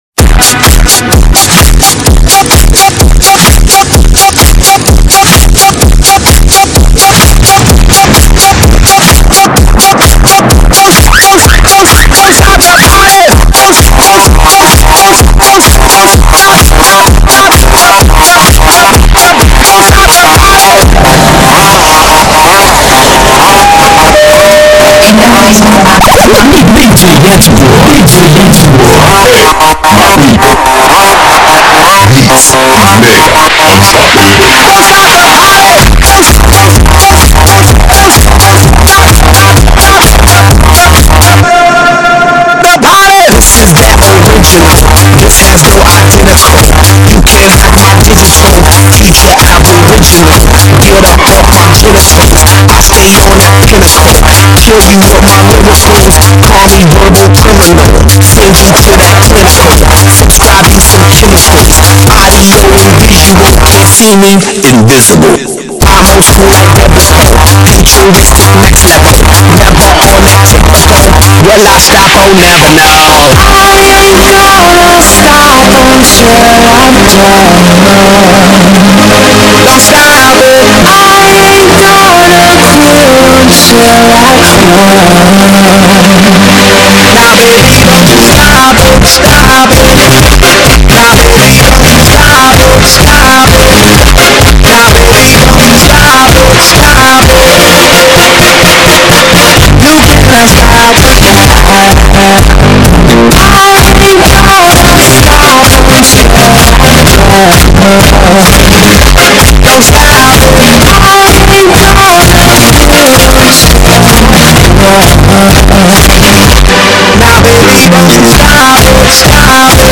hip rap.